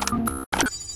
osiprReload.ogg